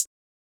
Closed Hats
Explosion Hat.wav